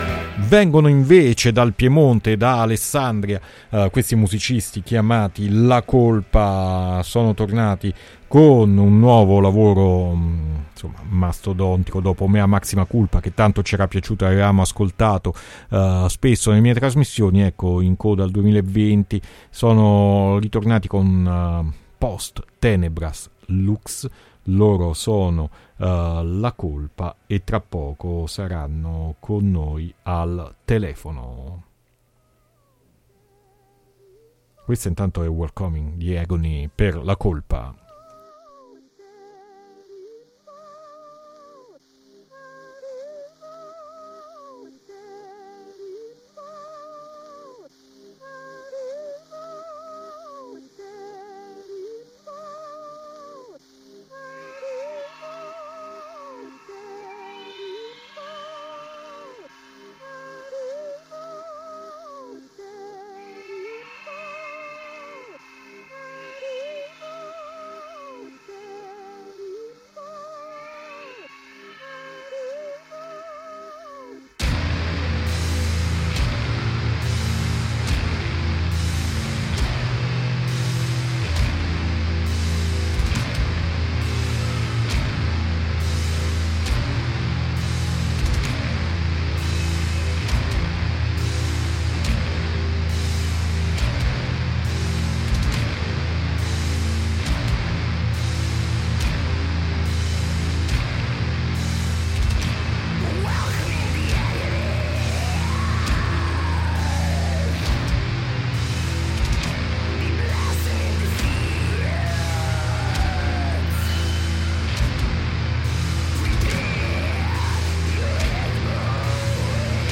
Intervista LaColpa | Puzzle 4-1-2021